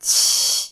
雅文檢測音
q_-15db.mp3